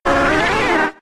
Cri de Rapasdepic K.O. dans Pokémon X et Y.